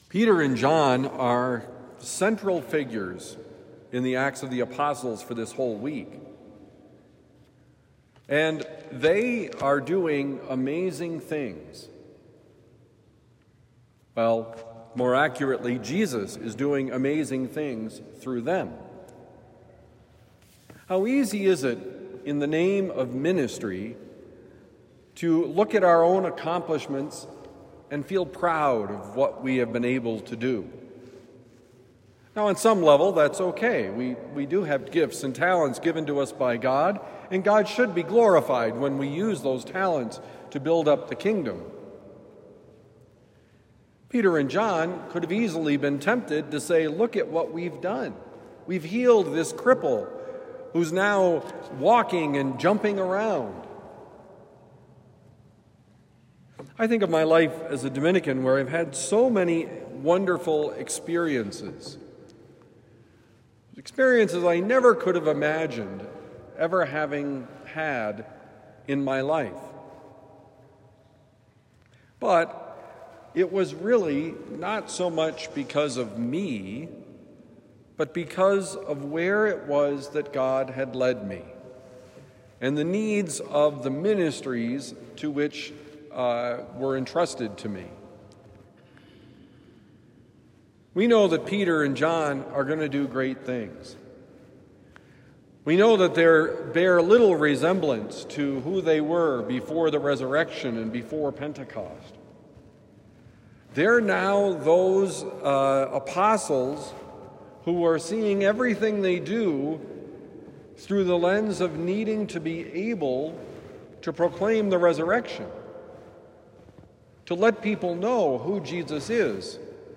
We did not do it: Homily for Thursday, April 24, 2025